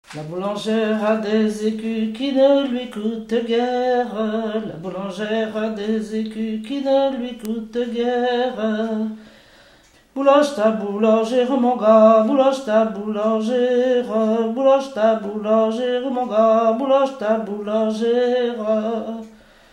Localisation Aubigny
Genre laisse
Pièce musicale inédite